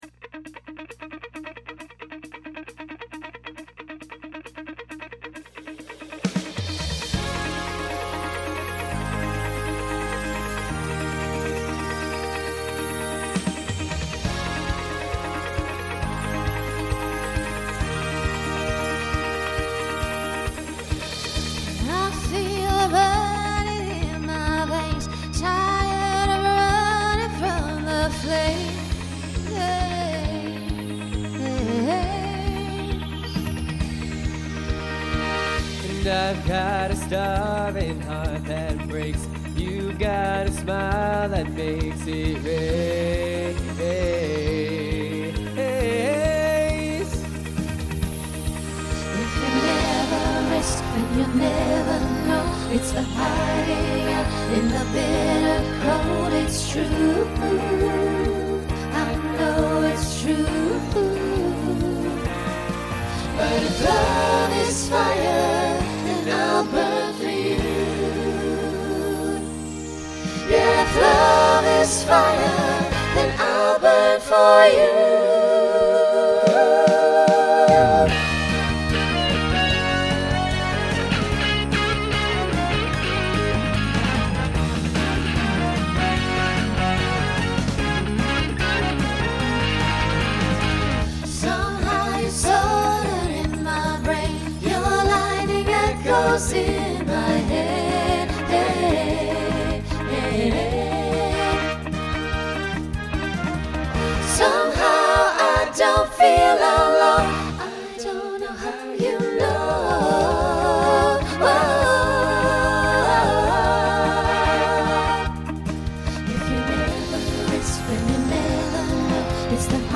Pop/Dance , Rock
Opener Voicing SATB